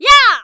qyh长声5.wav
qyh长声5.wav 0:00.00 0:00.45 qyh长声5.wav WAV · 39 KB · 單聲道 (1ch) 下载文件 本站所有音效均采用 CC0 授权 ，可免费用于商业与个人项目，无需署名。
人声采集素材